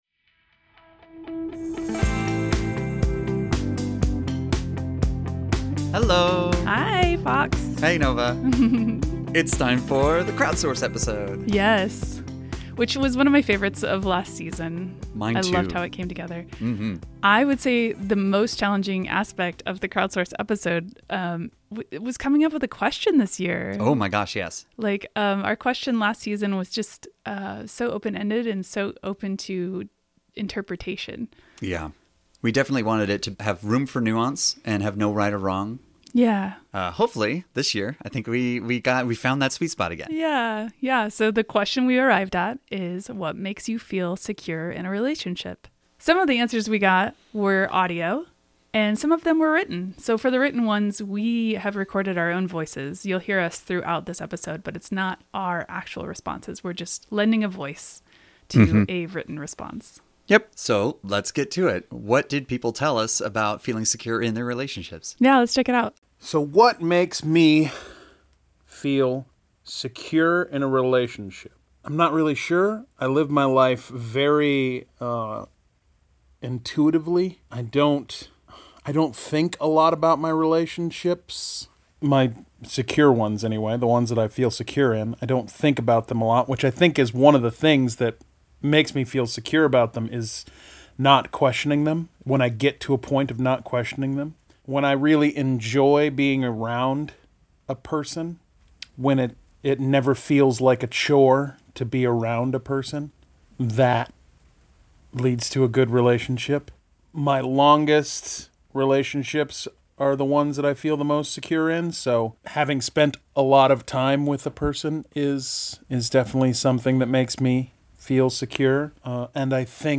A HUGE thank you to everyone who lent us their time, voices, thoughts and personal stories that we are honored to share with you this week!